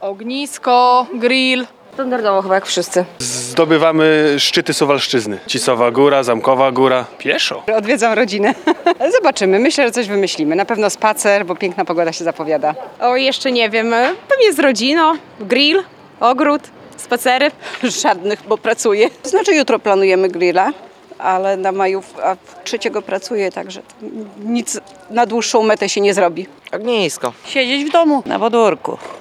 Tradycyjnie, czyli przy grillu, na świeżym powietrzu, lub w domu – tak suwalczanie zamierzają spędzić długi weekend. Niektórzy będą musieli pracować. Zapytaliśmy w poniedziałek (30.04) mieszkańców miasta o ich plany na majówkę.
sonda.mp3